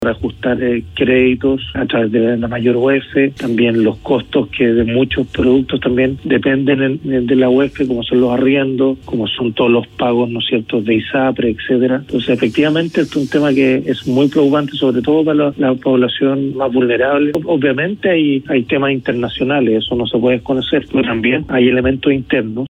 Habló al respecto el ministro de Hacienda, Rodrigo Cerda, quien dijo que pese a as mejores cifras de empleo, el Gobierno esta preocupado con la inflación ya que en consecuencia todos los elementos que se miden en UF irán al alza.